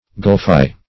Gulfy \Gulf"y\ (g[u^]lf"[y^])